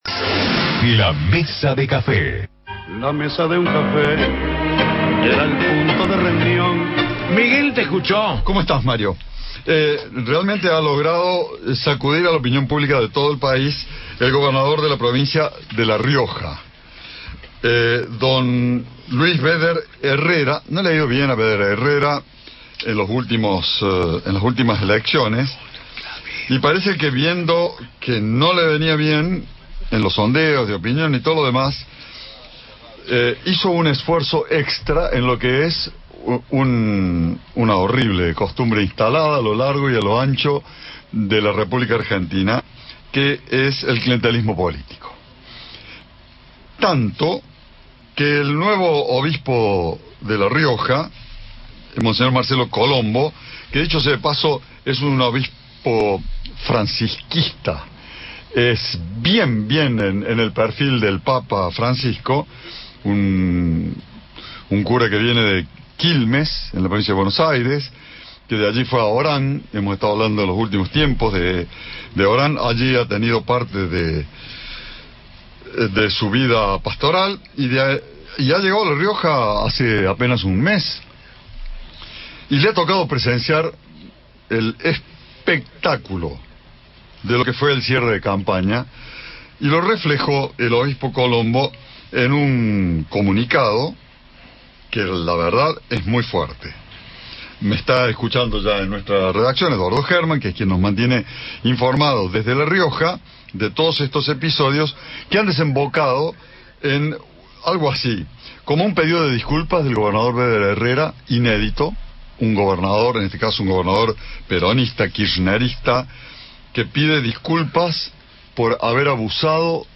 El programa Juntos, que conduce Mario Pereyra, centró el espacio La Mesa de Café bajo la temática «Dádivas en La Rioja», tras el arrepentimiento del gobernador Luis Beder Hererra. En la oportunidad, el diputado nacional radical, Julio Martínez, dijo que no cree en el arrepentimiento del gobernador.